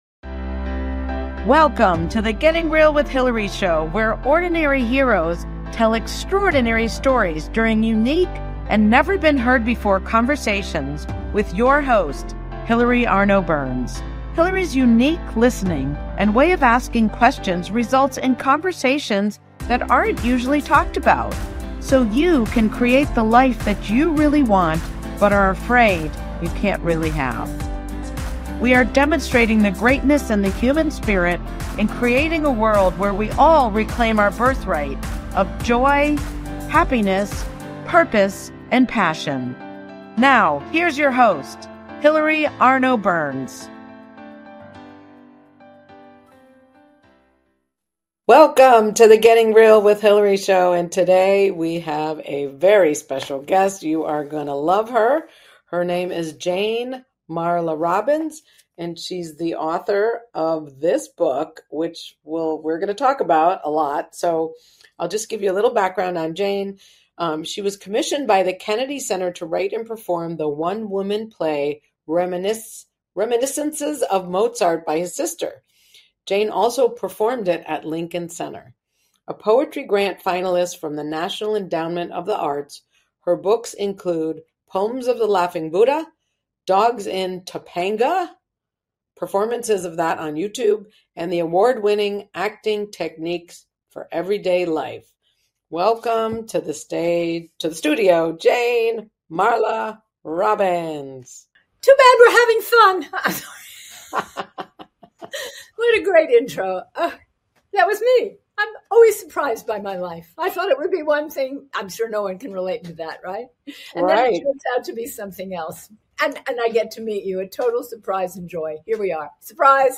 What a wonderful conversation.